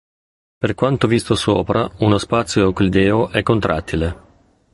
Pronunciat com a (IPA) /ew.kliˈdɛ.o/